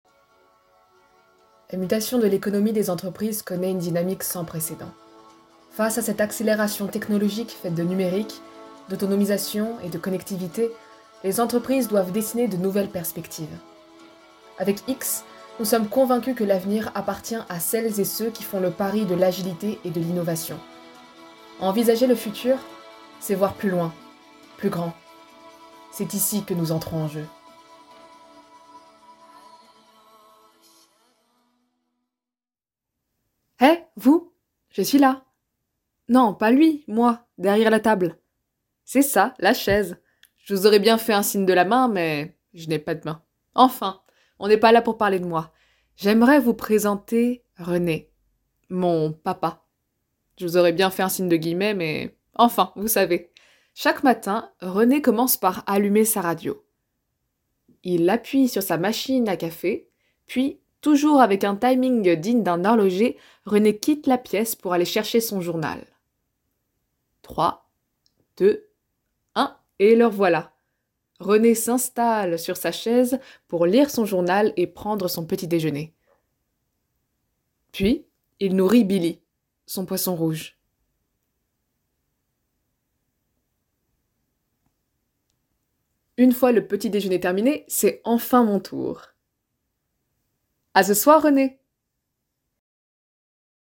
Voix off
- Mezzo-soprano